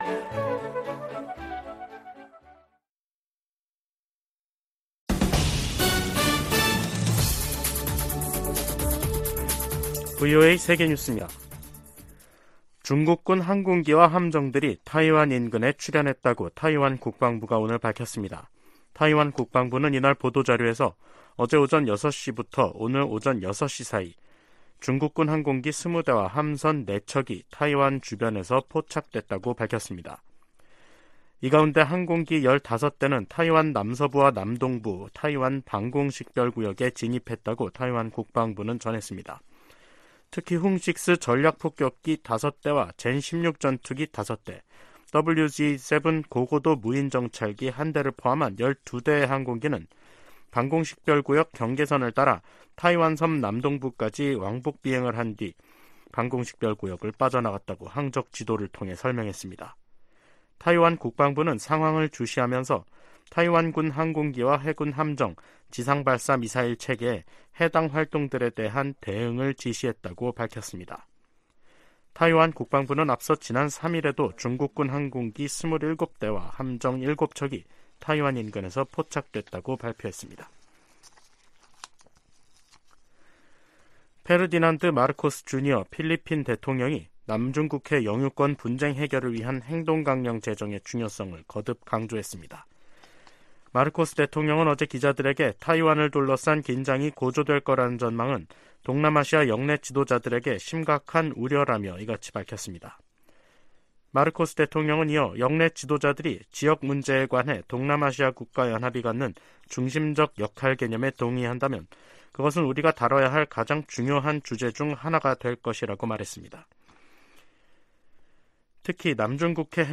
VOA 한국어 간판 뉴스 프로그램 '뉴스 투데이', 2023년 5월 10일 2부 방송입니다. 미 국무부는 ‘미한일 미사일 정보 실시간 공유 방침’ 관련 일본 언론 보도에 대해, 비공개 외교 대화 내용을 밝히지 않는다면서도, 3각 공조는 필수라고 강조했습니다.